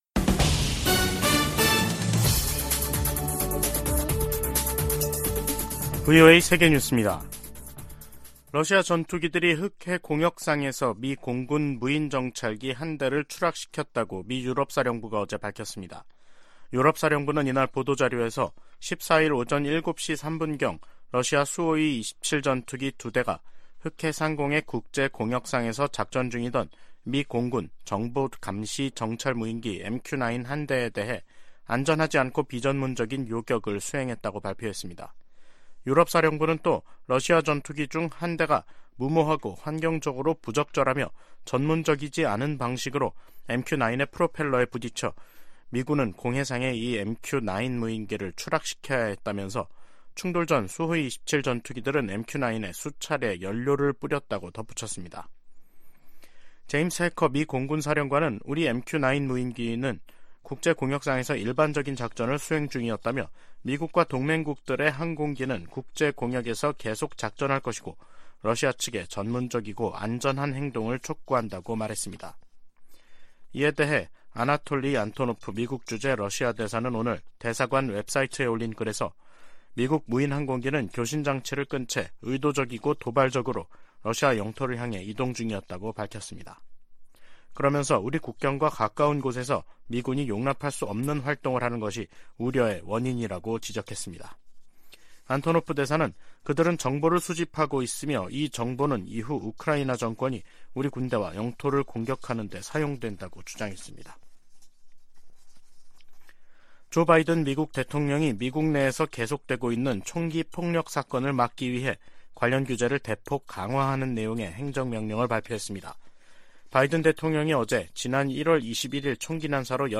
세계 뉴스와 함께 미국의 모든 것을 소개하는 '생방송 여기는 워싱턴입니다', 2023년 3월 15일 저녁 방송입니다. '지구촌 오늘'에서는 흑해 상공에서 미국 무인기가 러시아 전투기의 도발에 추락한 이야기 전해드리고, '아메리카 나우'에서는 총기 구매자 신원조회 강화 행정명령 관련 소식 살펴보겠습니다.